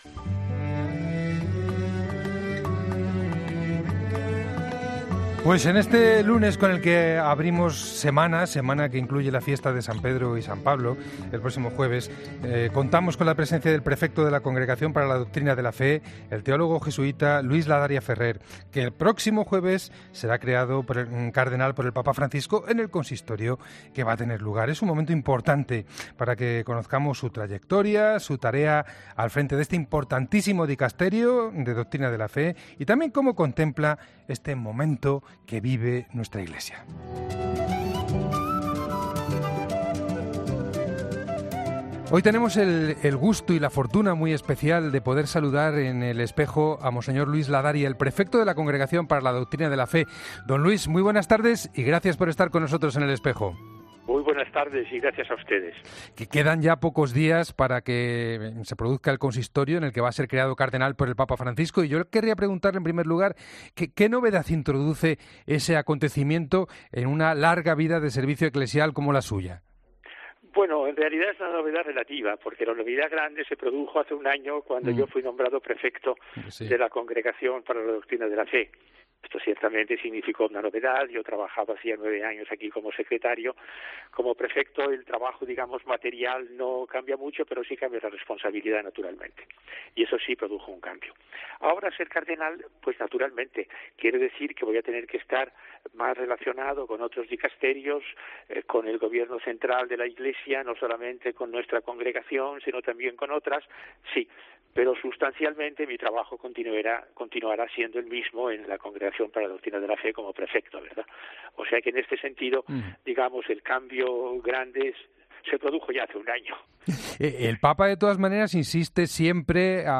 El prefecto de la Congregación para la Doctrina de la Fe atiende la llamada de 'El Espejo' días antes de ser creado cardenal.